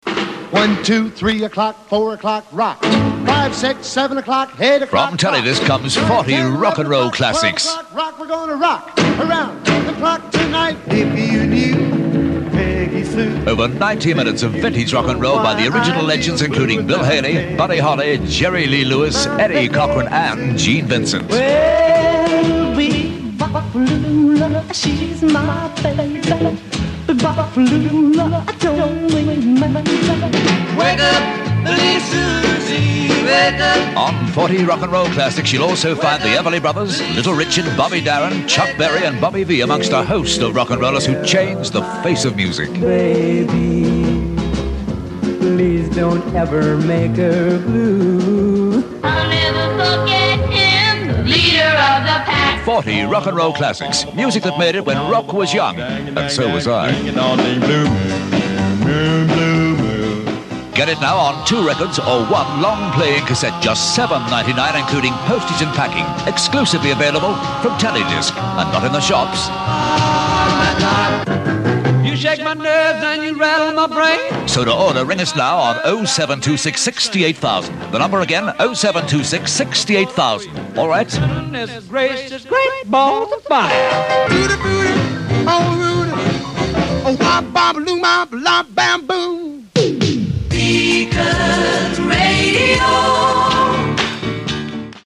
The VO is the legendary Alan Freeman.